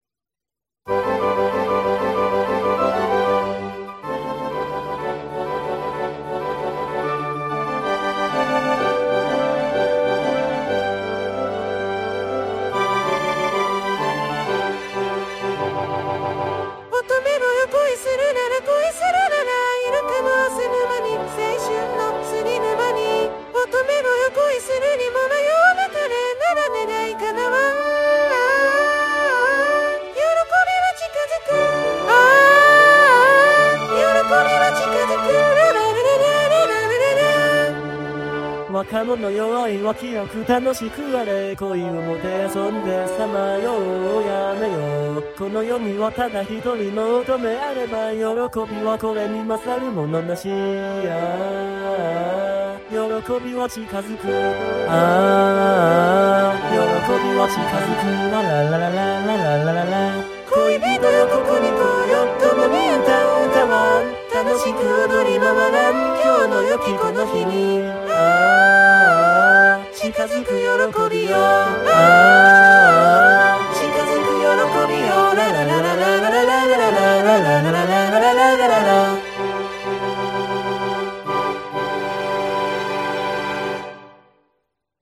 男声はGackpoidかKaitoを、女声は初音ミクNTを使いました。
管弦楽はGarritan Personal Orchestra5(VST)を使ってMP3形式で保存したものです。